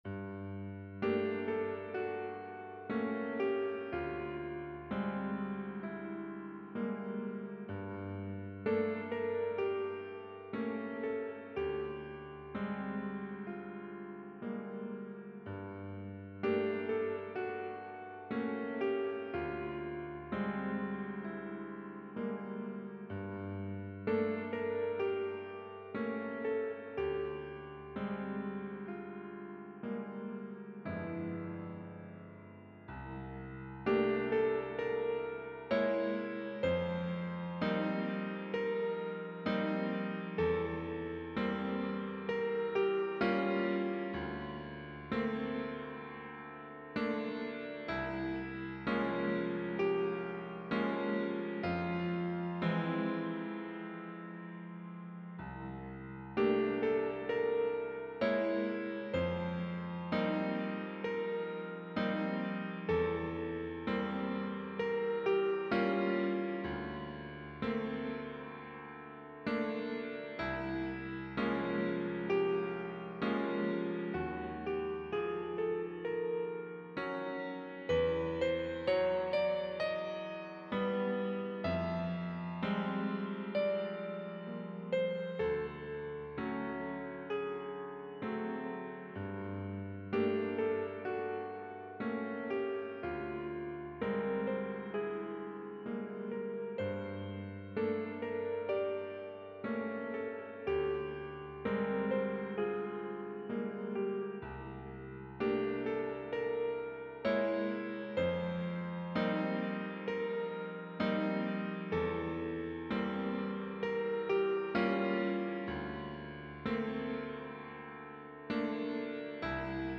TWO GNOSSIENNES - Piano Music, Solo Keyboard - Young Composers Music Forum
As I love Satie, sometimes I "try to copy" his style, and I play with harmonies and chords...